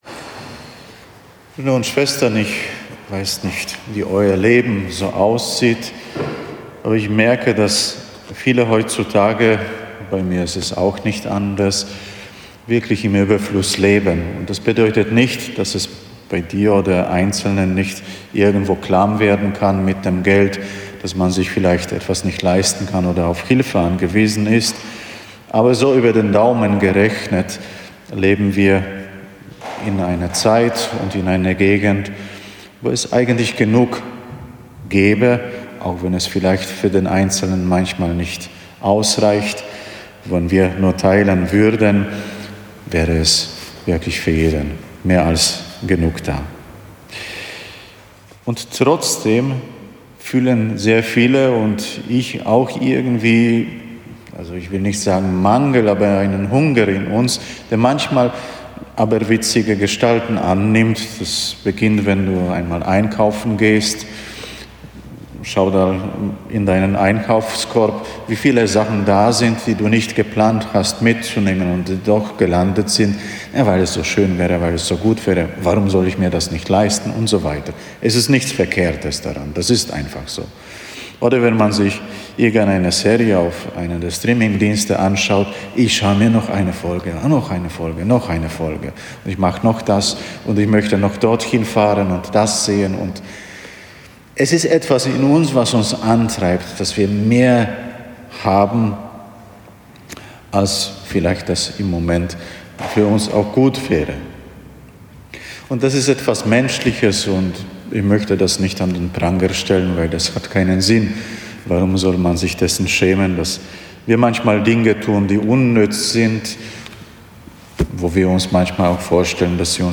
Eine Predigt zum 17. Sonntag im Jahreskreis B